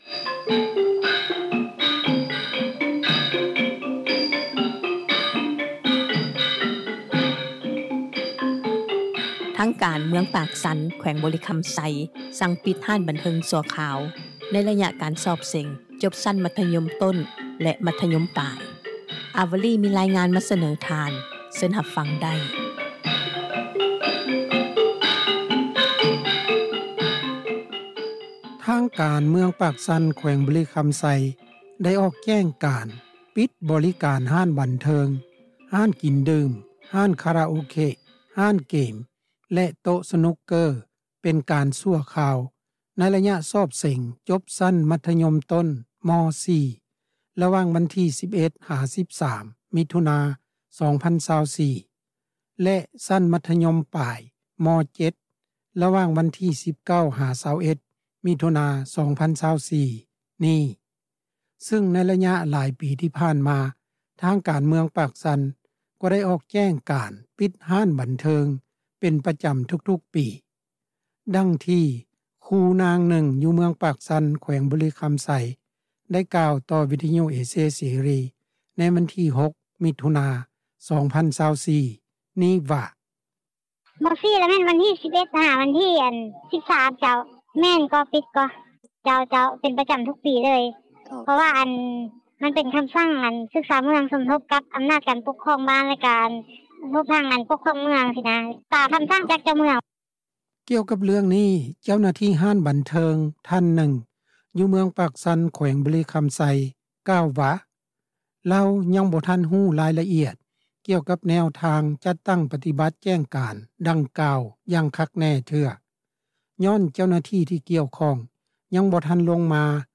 ນັກຂ່າວພົນລະເມືອງ